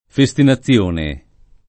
festinazione [ fe S tina ZZL1 ne ] s. f.